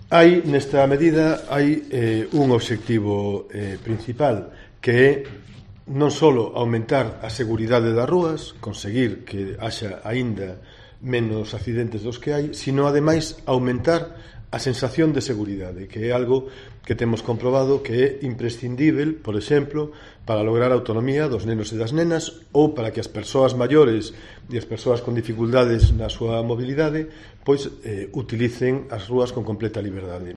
El concejal de Movilidade, Demetrio Gómez, presenta la medida de los 10 km/h en Pontevedra